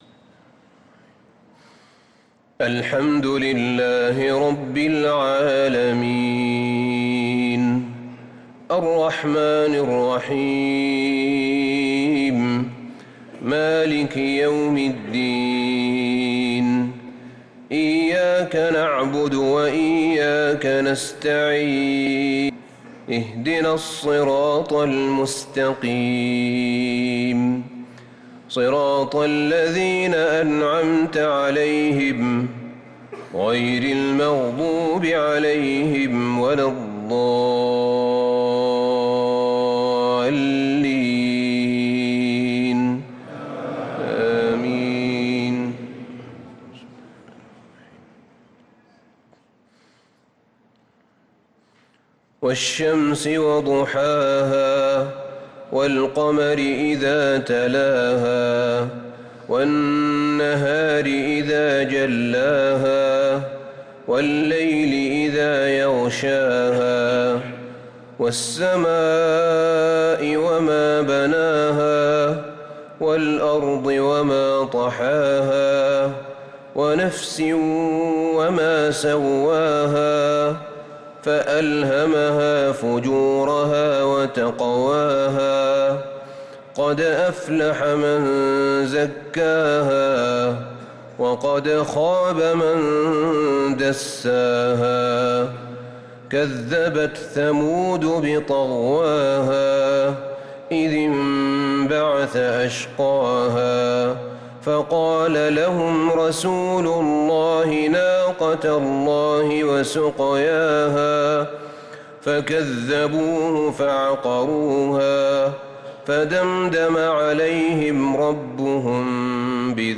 صلاة العشاء للقارئ أحمد بن طالب حميد 27 رمضان 1445 هـ
تِلَاوَات الْحَرَمَيْن .